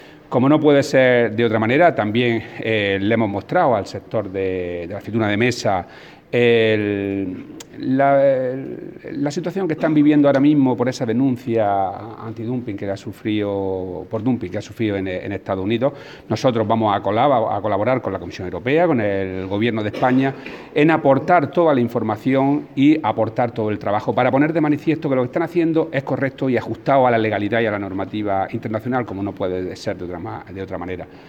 Declaraciones del consejero sobre aceituna de mesa